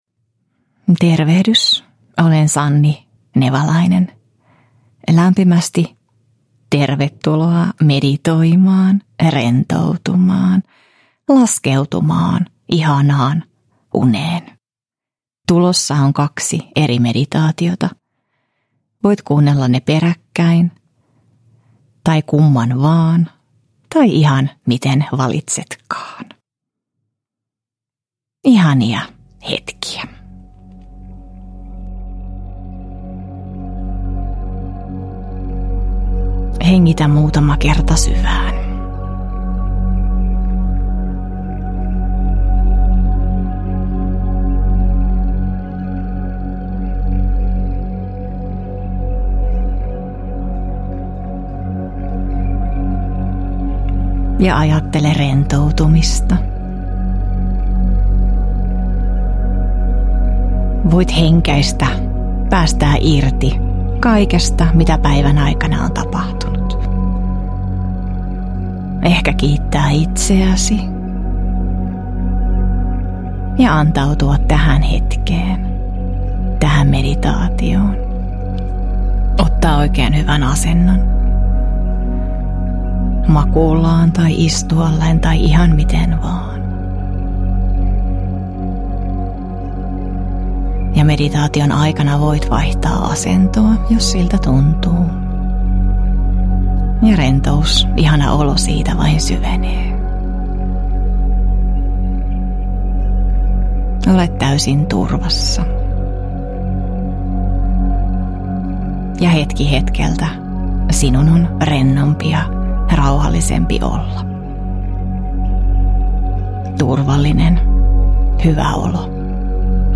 Nuku hyvin -meditaatiot – Ljudbok – Laddas ner